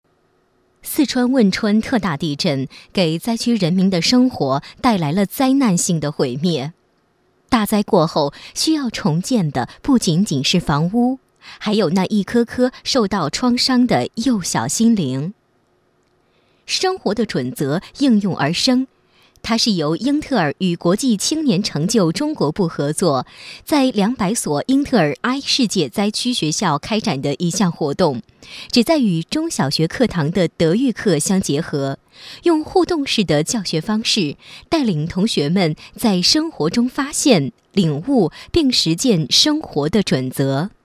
女国23_专题_政府_四川地震_亲切.mp3